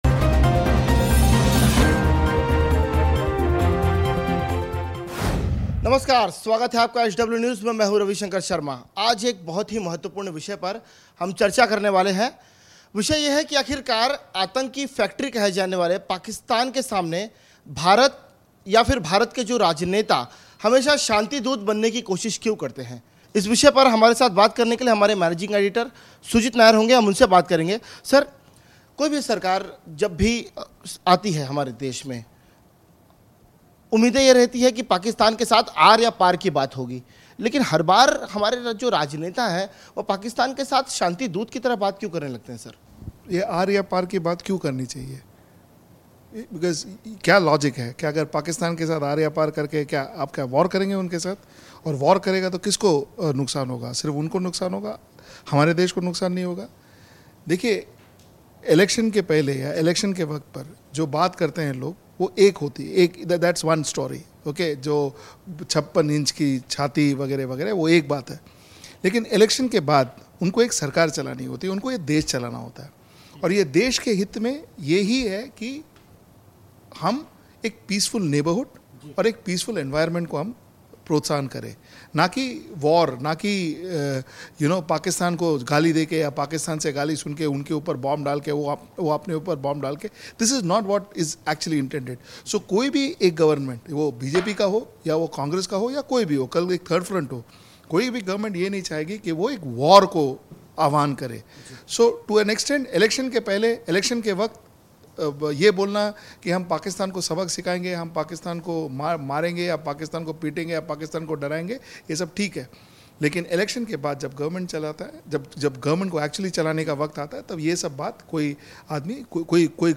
News Report / कश्मीर मुद्दे पर पीएम मोदी की नीतियों की कलई खुल रही है